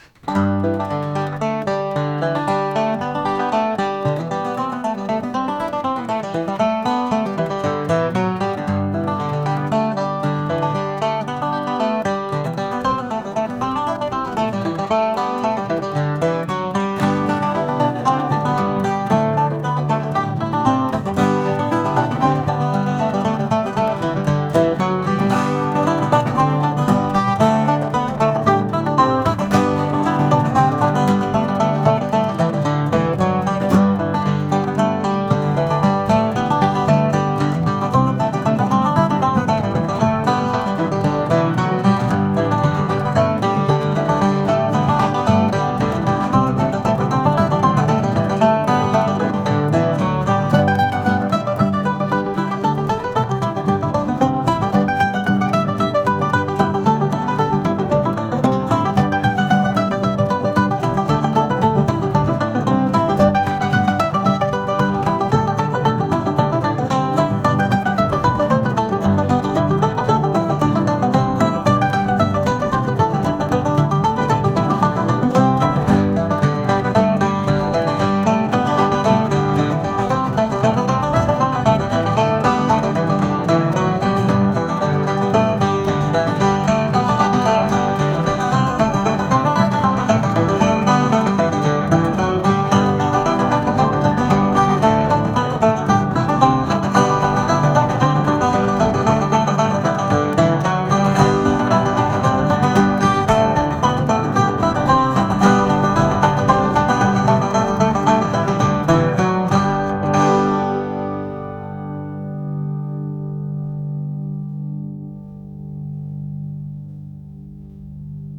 folk | country | acoustic